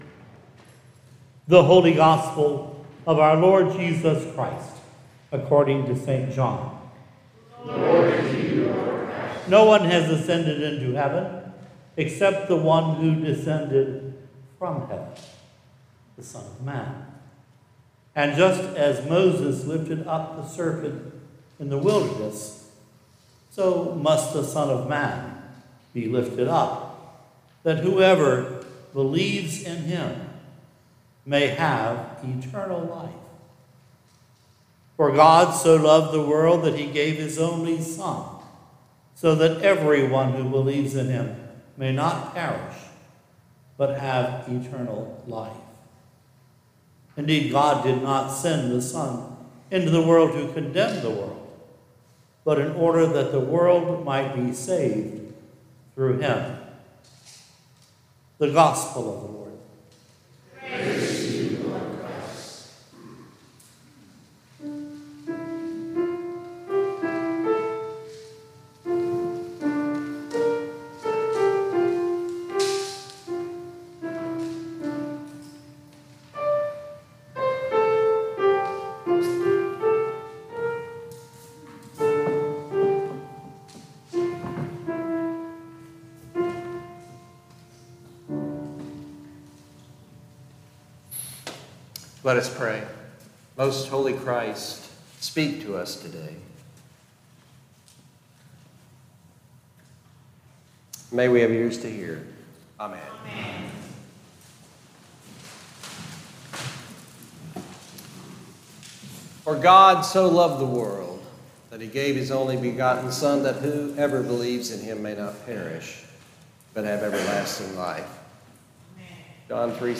Latest Sermons & Livestreams